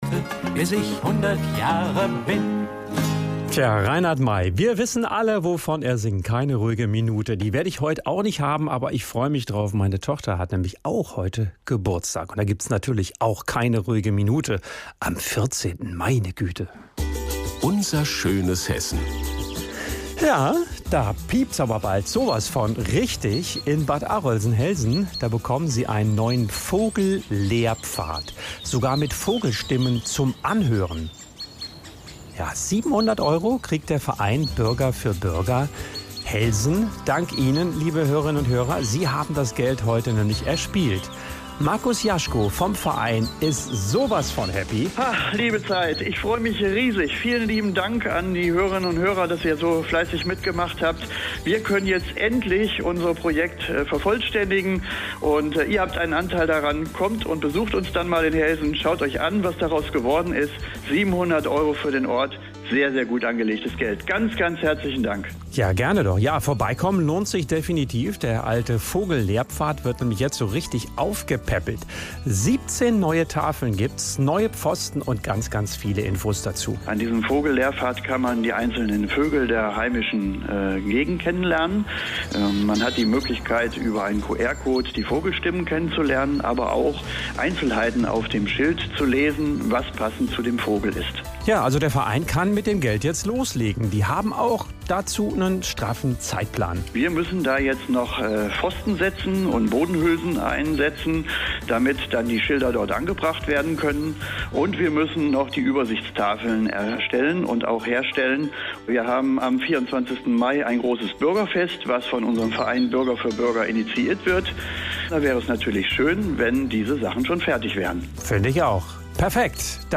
Am Dienstag, 22.04. wurde ich interviewt. Ich stellte unsere Planungen zur alten Vogelfahrt und künftigen Vogel- und Naturlehrpfad vor.